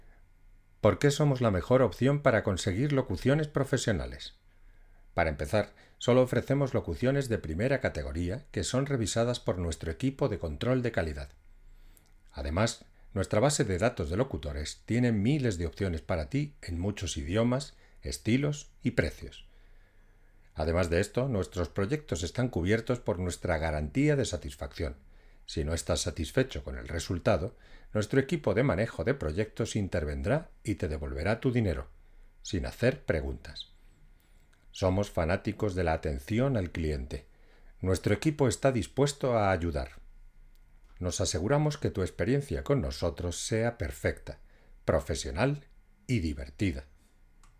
actor de teatro y doblaje
Actor de doblaje,locutor de radio y publicidad,todo tipo de locuciones para vídeos corporativos, voice over,documentales...
Sprechprobe: Industrie (Muttersprache):
ESTUDIO GRABACIÓN.mp3